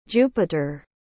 Planeta Tradução em Inglês Pronúncia